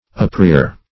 Uprear \Up*rear"\, v. t.